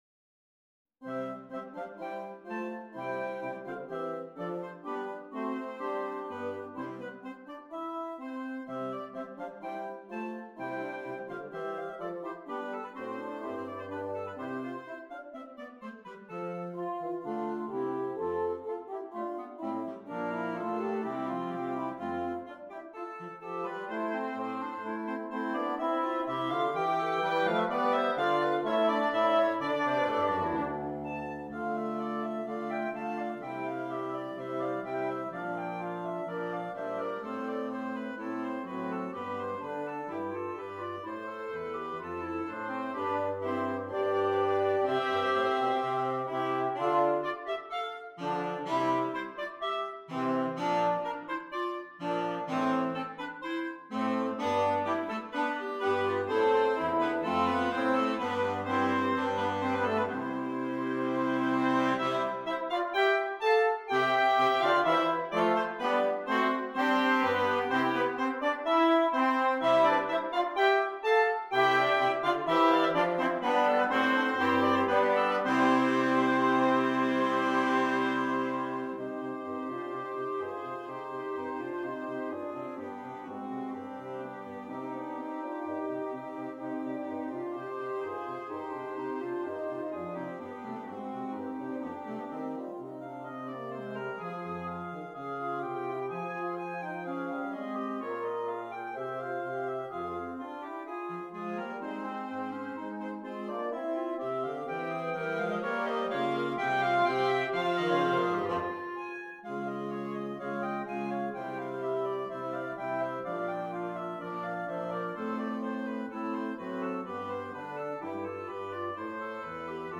Interchangeable Woodwind Ensemble
PART 1 - Flute, Oboe, Clarinet
PART 5 - Baritone Saxophone, Bass Clarinet, Bassoon